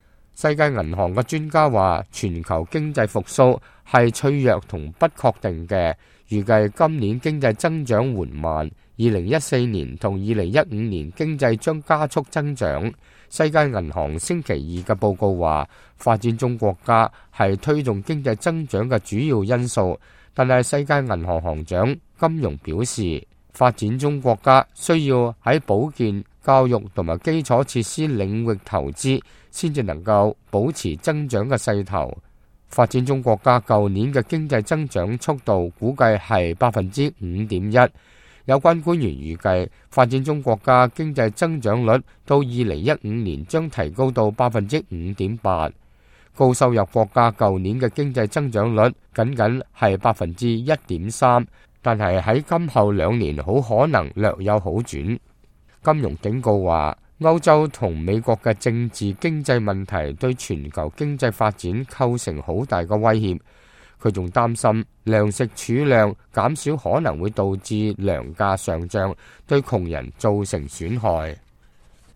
世界銀行行長金鄘2012年10月15日在韓國首爾新聞發佈會上講話